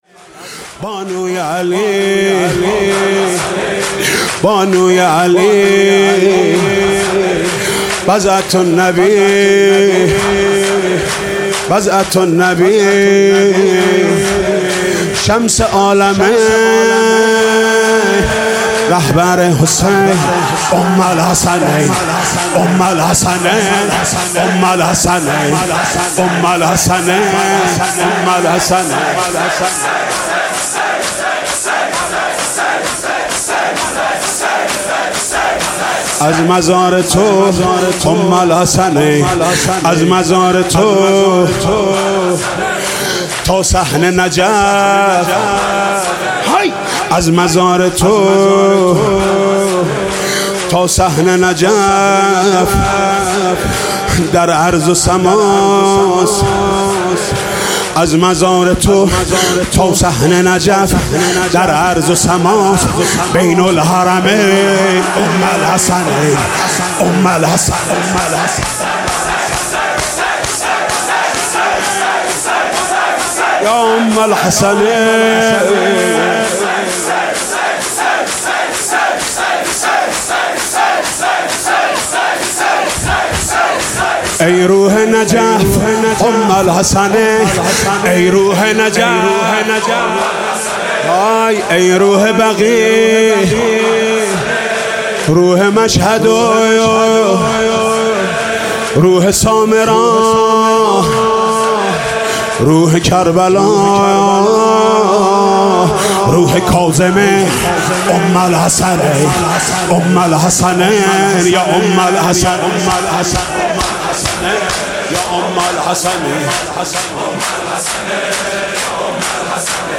مداحی و نوحه
مداحی شب اول فاطمیه ۱۳۹۶